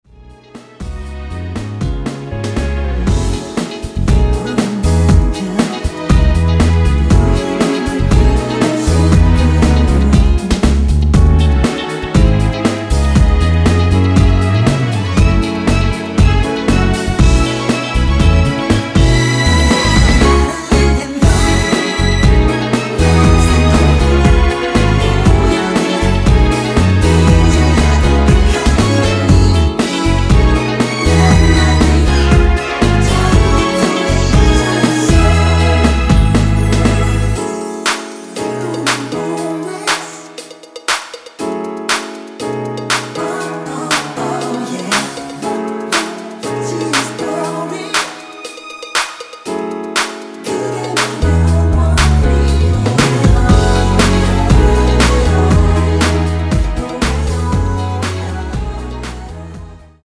원키에서(+2)올린 코러스 포함된 MR입니다.
◈ 곡명 옆 (-1)은 반음 내림, (+1)은 반음 올림 입니다.
앞부분30초, 뒷부분30초씩 편집해서 올려 드리고 있습니다.